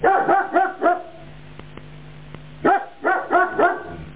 alsation.mp3